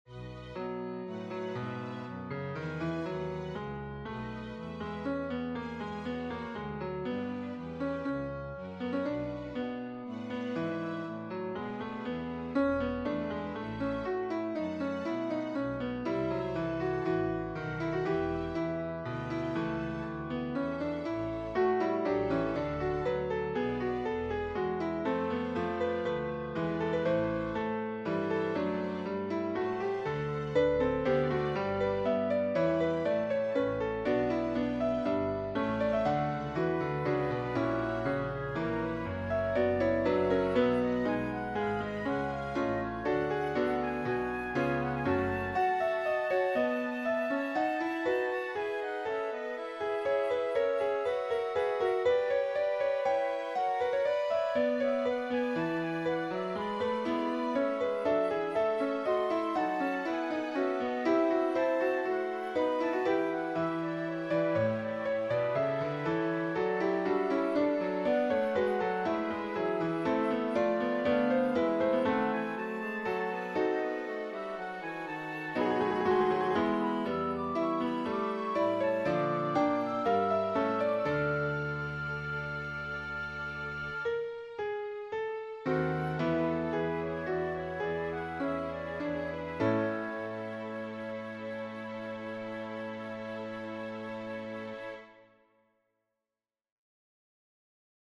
MP3 Dateien von allen Chorstücken nach Register
BWV234-1c Kyrie_II_SATB.mp3